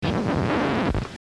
scratch4.mp3